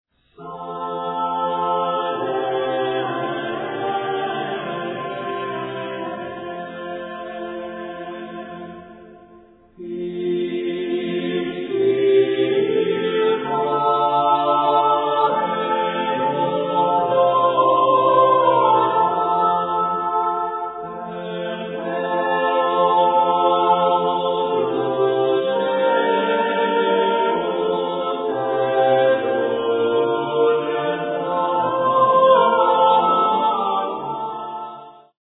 Renaissance Polyphony